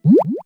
BW2_BagSound.WAV